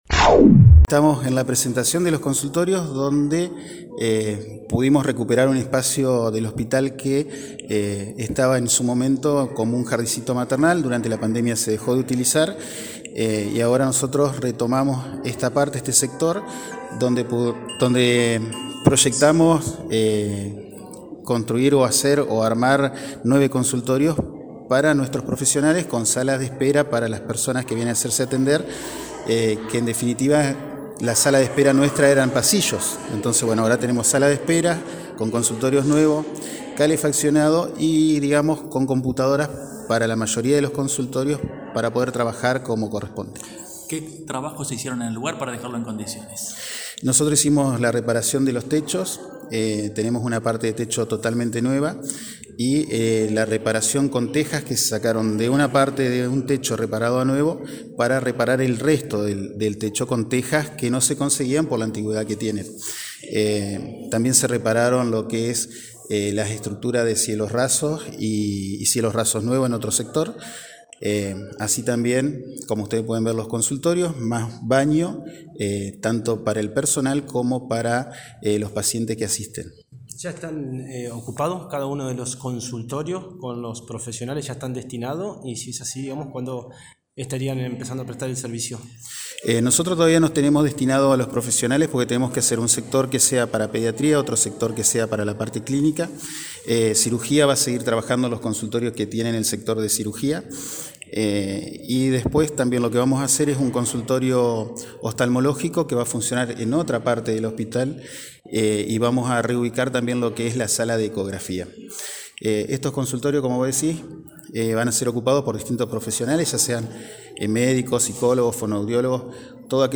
conferencia-hospital.mp3